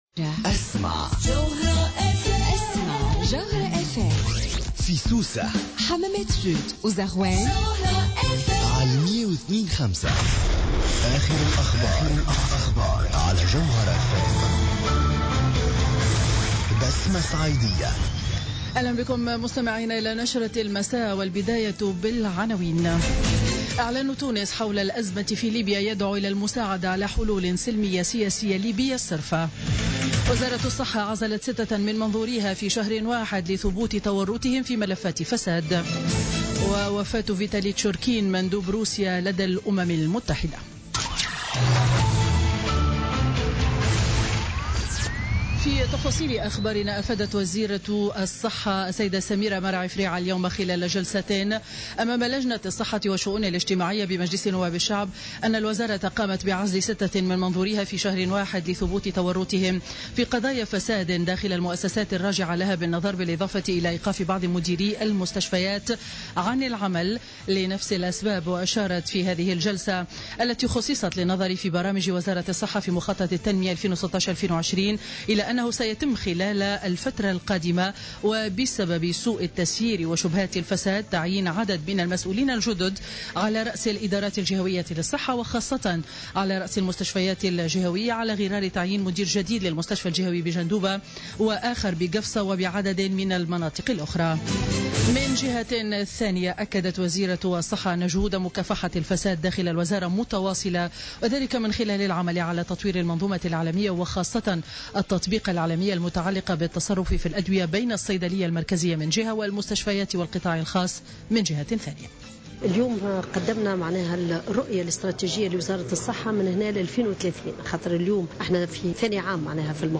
نشرة أخبار السابعة مساء ليوم الاثنين 20 فيفري 2017